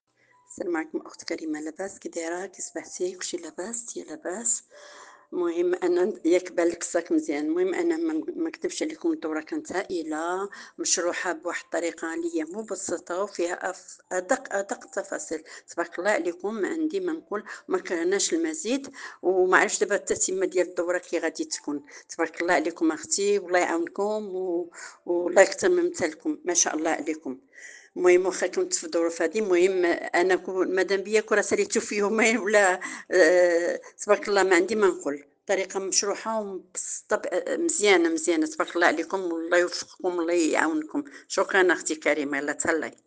شهادات صوتية لبعض المشاركات 👇👇👇